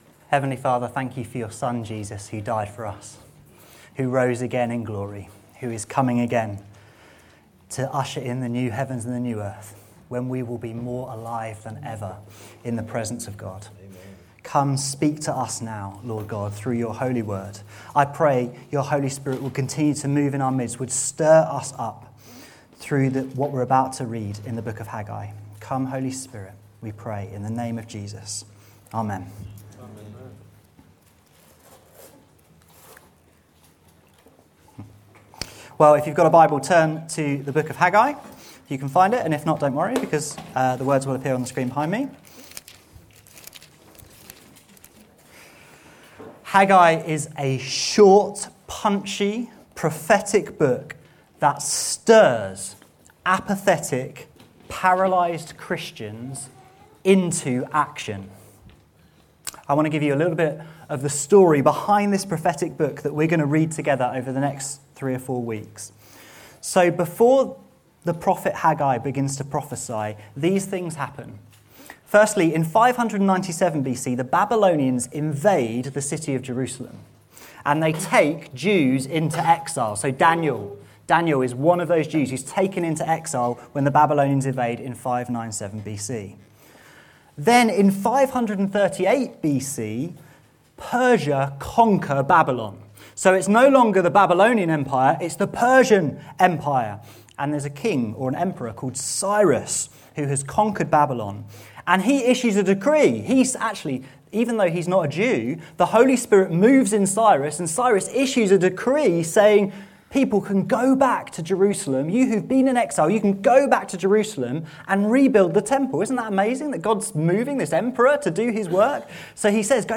This sermon proclaims the almighty, powerful, and glorious Lord of Hosts words to us all, spoken through Haggai.